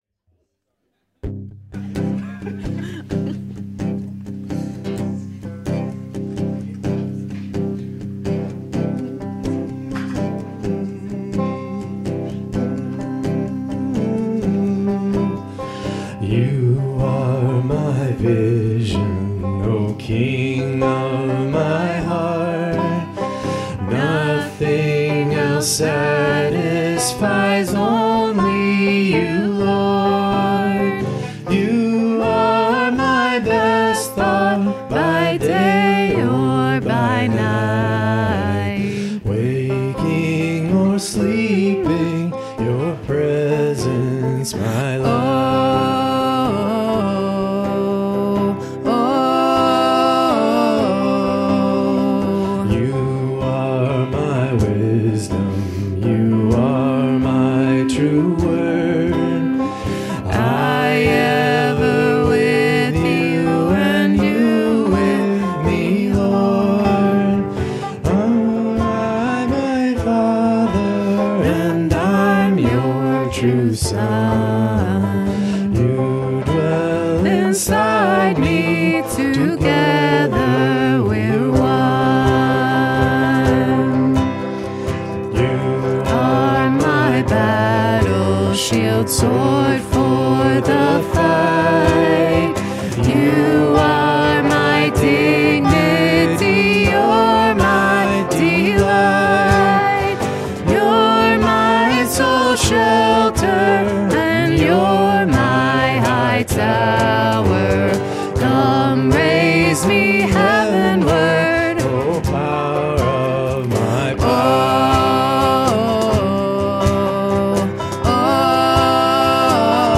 Worship 2025-01-05